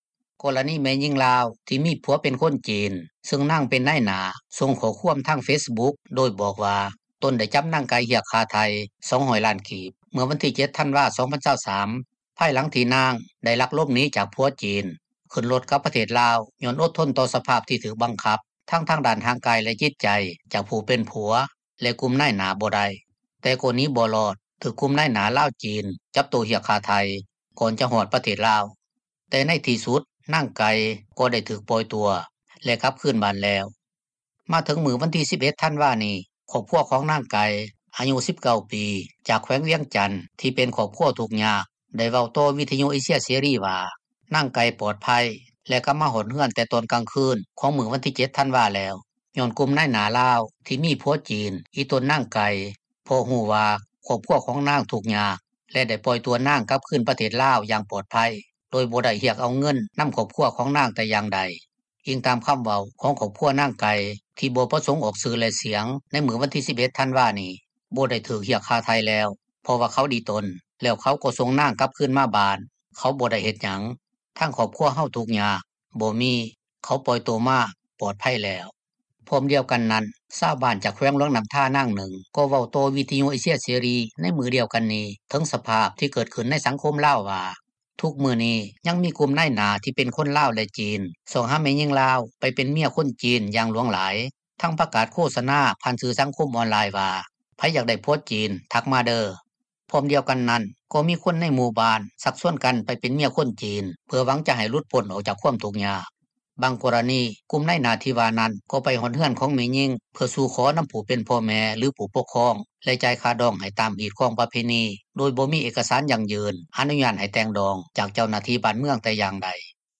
ດັ່ງຊາວລາວ ຈາກແຂວງອຸດົມໄຊ ເວົ້າຕໍ່ວິທຍຸເອເຊັຽເສຣີ ໃນມື້ວັນທີ 11 ທັນວານີ້ວ່າ: